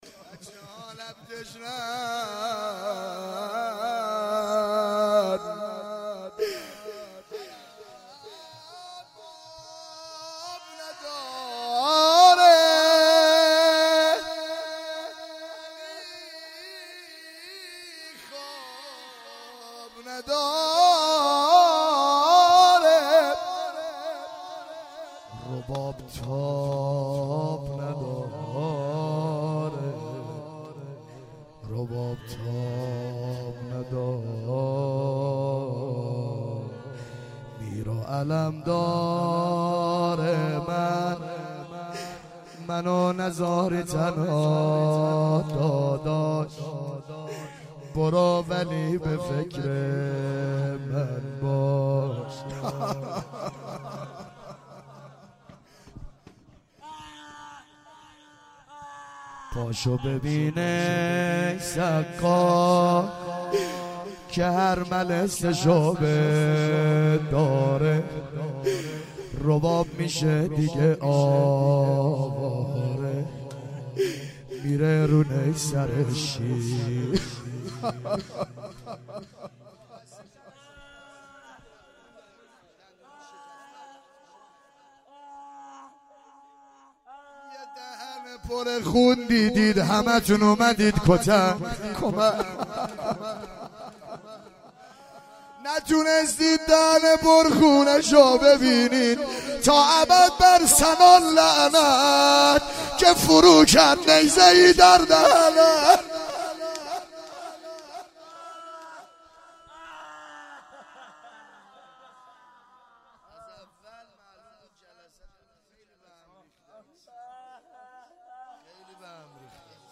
وفات حضرت معصومه-جمعه30آذر97
هیئت زواراباالمهدی(ع) بابلسر